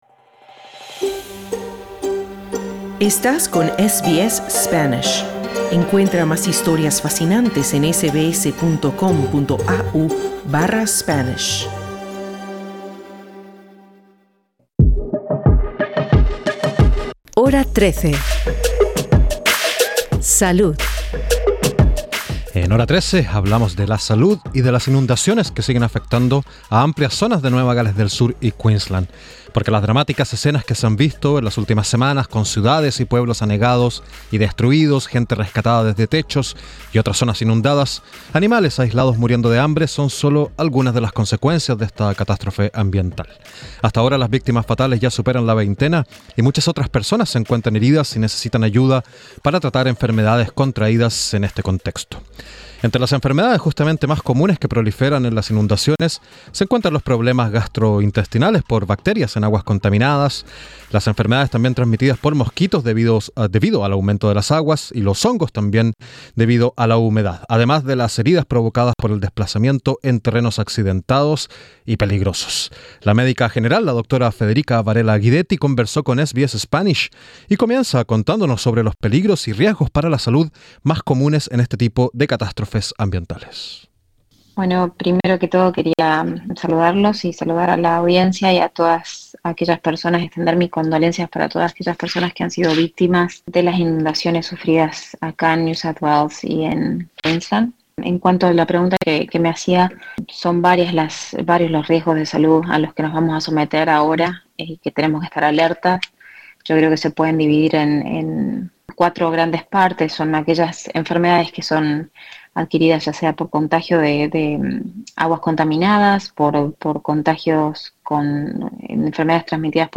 Escucha la entrevista con la médica general